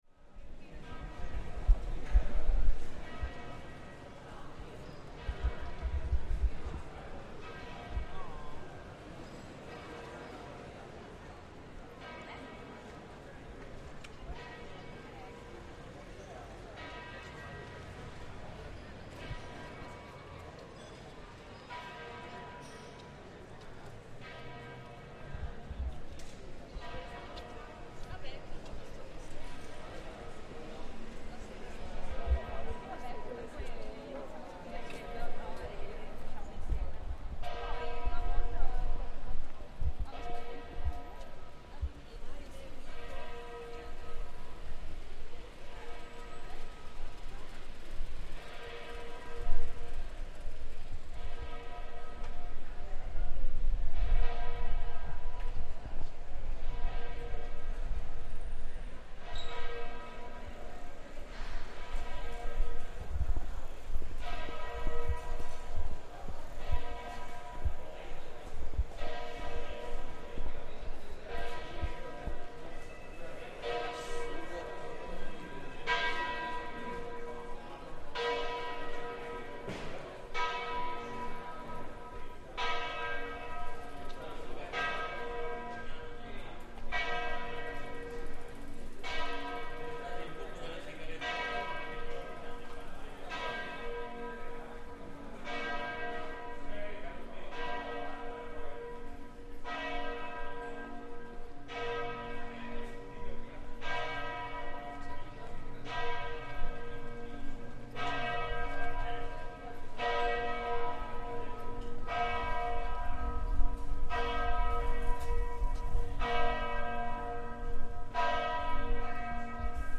Bells for midday chime first in the Duomo cathedral in Treviso, Italy, then we walk down to the main Piazza dei Signore e Signori to heard the noon bells there too.